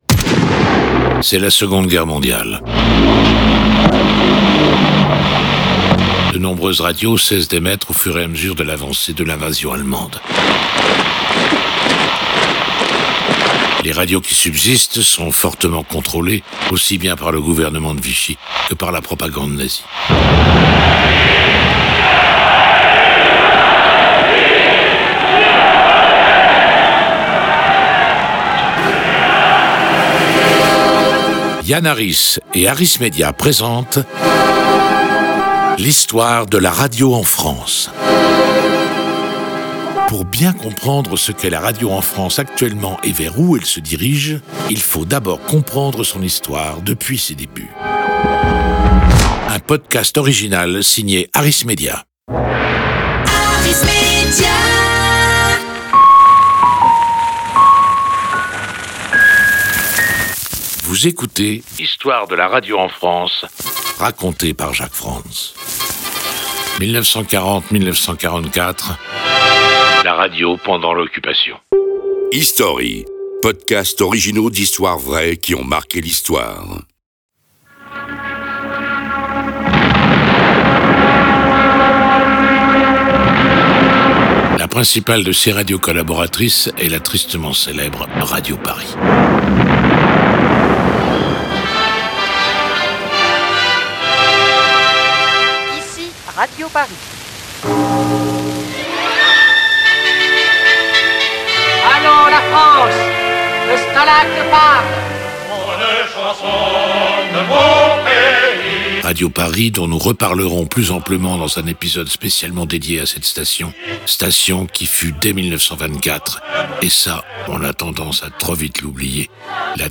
La Radio Pendant L'Occupation (1940-1944), raconté par JACQUES FRANTZ.